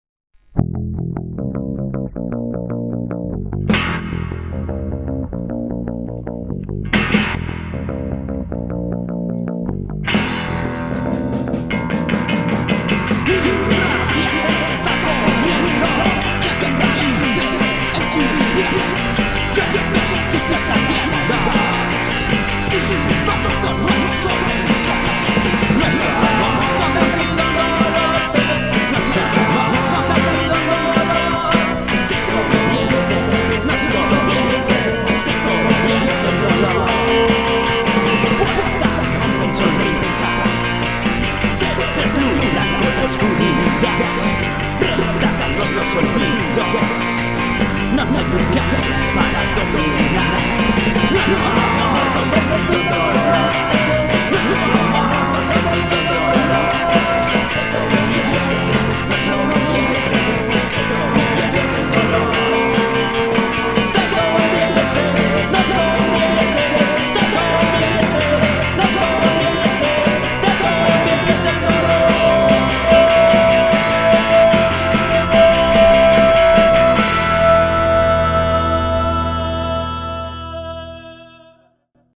Estilo: After Punk